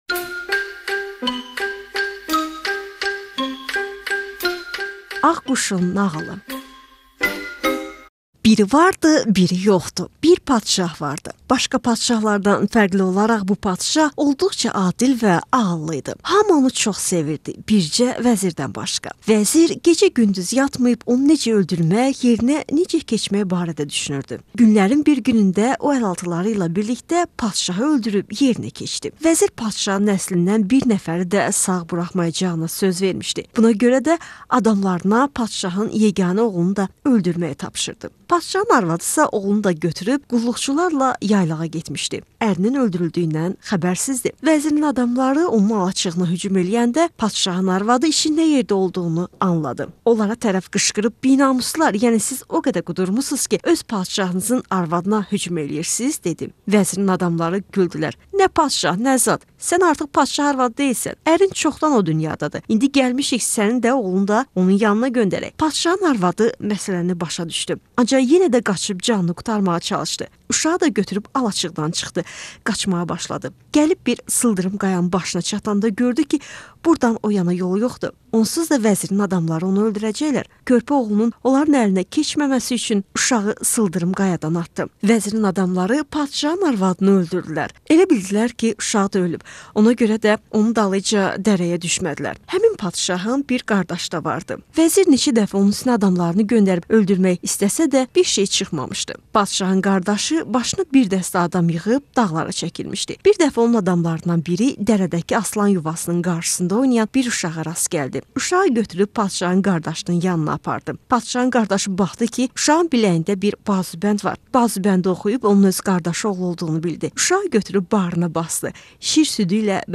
Azeri tale